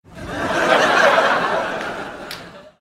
Laugh Sound Effects MP3 Download Free - Quick Sounds